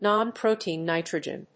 non-protein+nitrogen.mp3